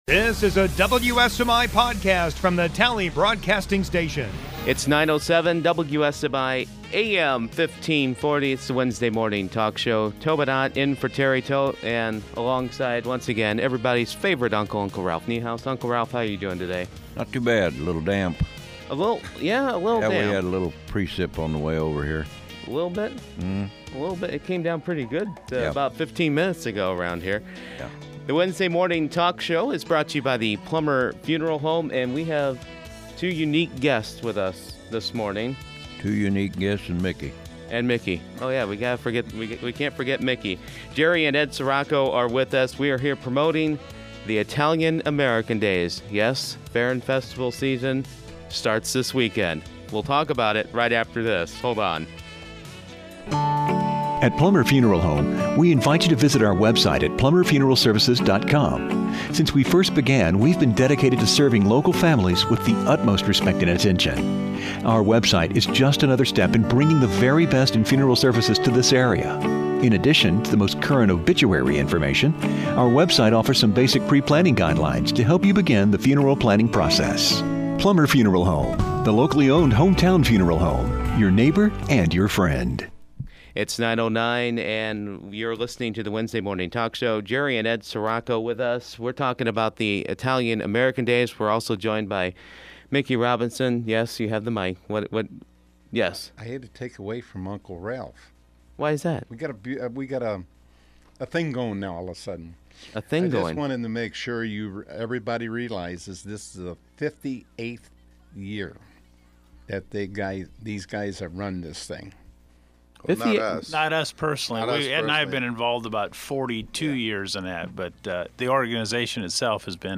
Podcasts - Wednesday Talk